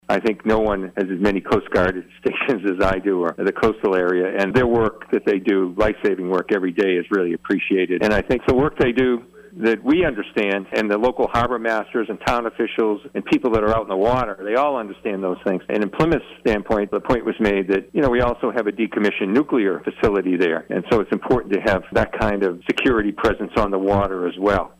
Keating says there are security concerns as well.